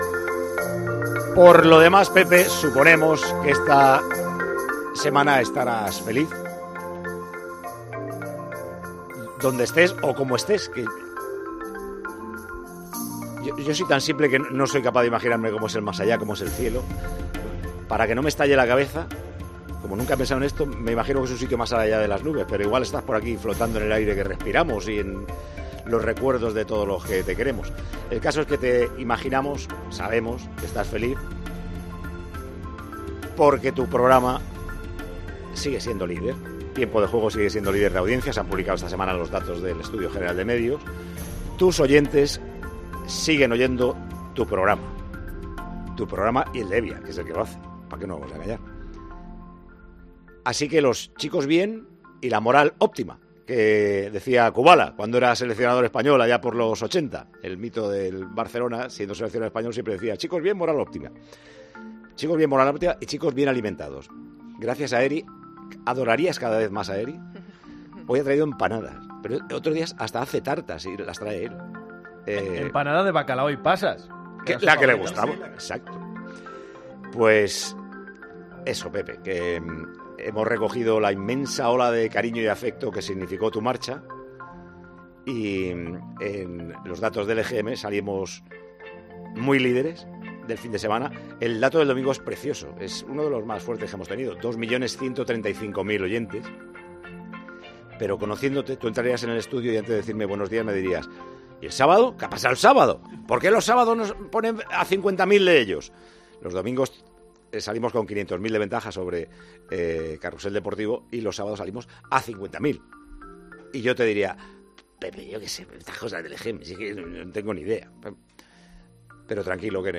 Paco González ha iniciado el primer programa de Tiempo de Juego tras el EGM recordando a Pepe Domingo Castaño y dedicándole el liderazgo: "Los chicos bien y la moral óptima".